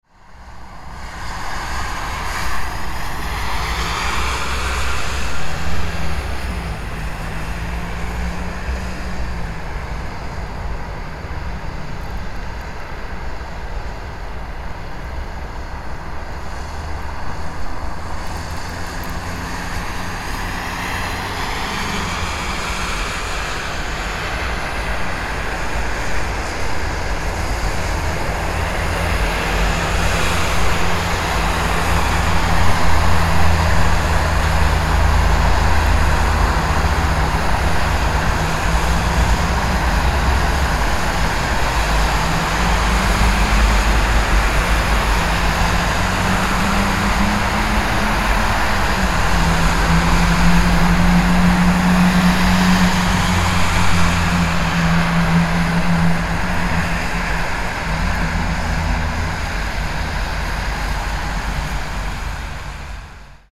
Winter City Traffic Sound Effect – Realistic Slushing Tires & Urban Ambience
Winter-city-traffic-sound-effect-realistic-slushing-tires-urban-ambience.mp3